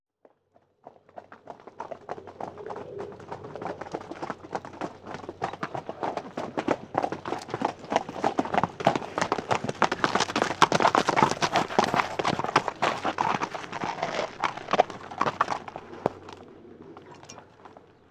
Dos caballos llegando al trote sobre tierra